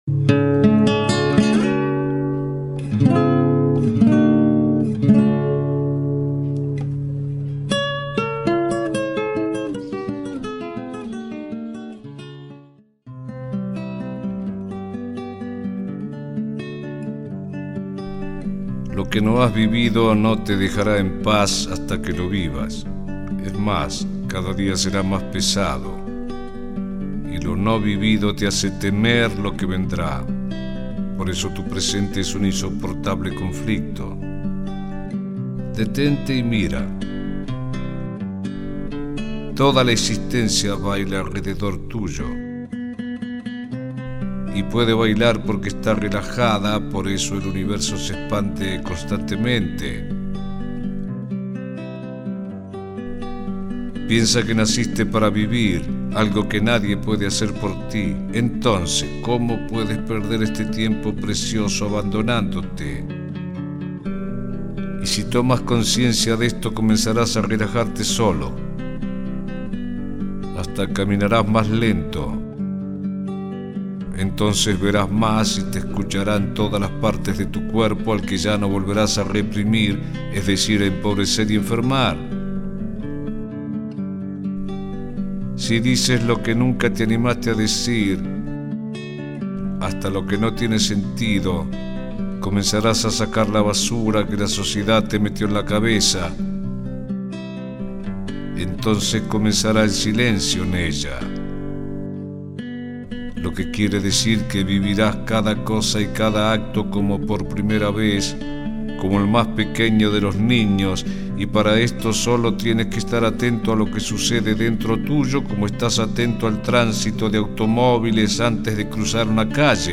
Carpeta: Folklore mp3